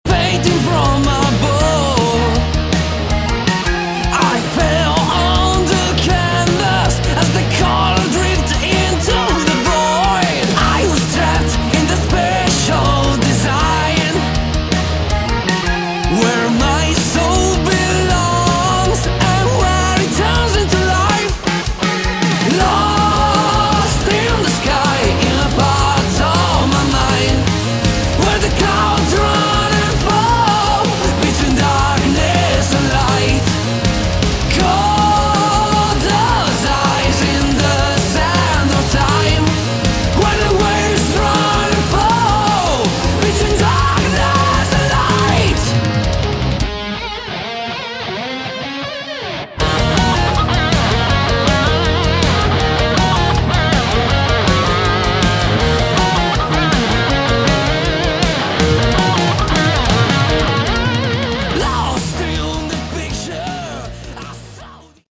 Category: Hard Rock
vocals
drums
guitars
bass
keyboards